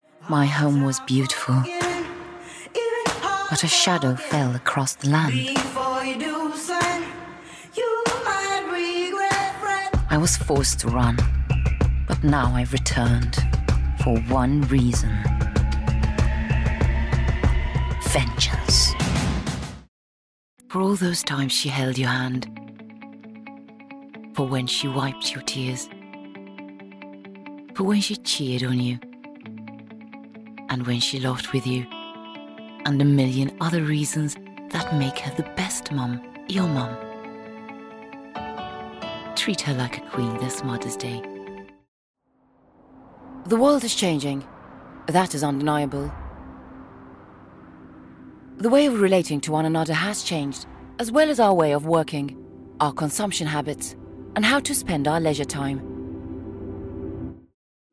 Maltese, Female, Home Studio, Teens-20s